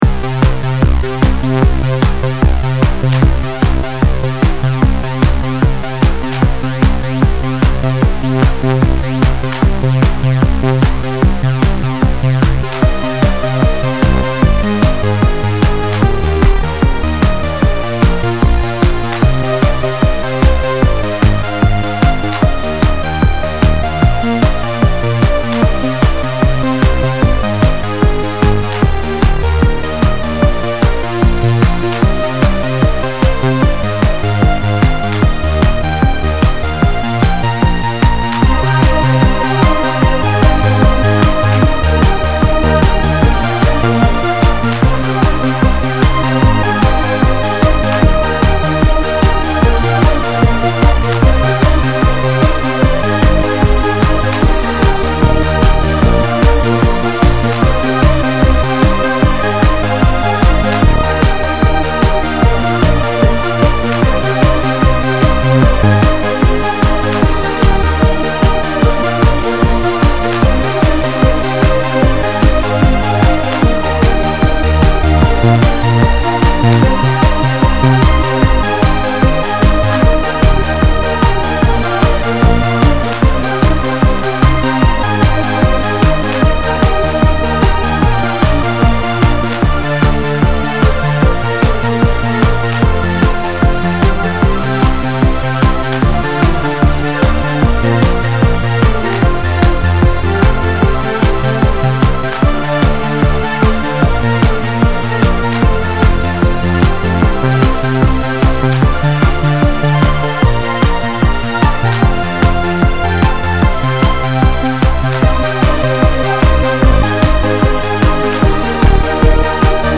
Style: Dance/Trance